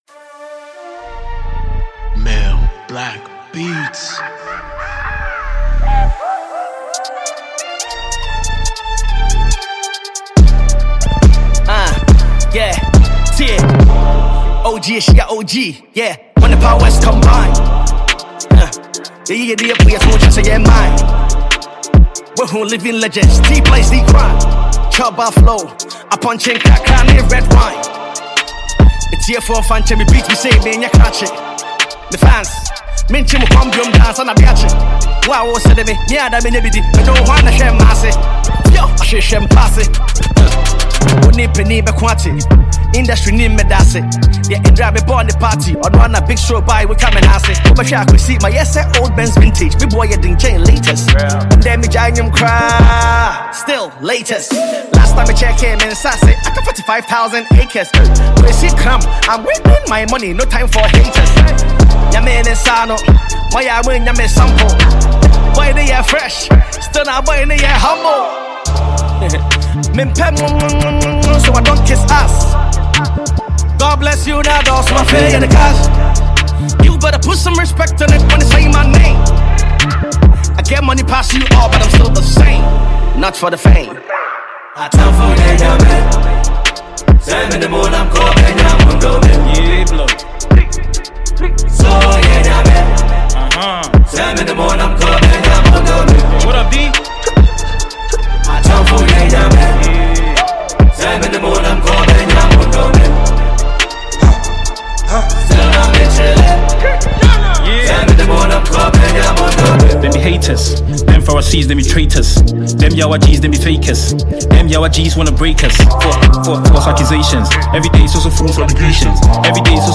Ghana MusicMusic
Legendary Ghanaian hip hop musician